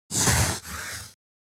Download Free Writing Sound Effects | Gfx Sounds
Pencil-writing-a-single-line.mp3